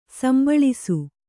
♪ sambaḷisu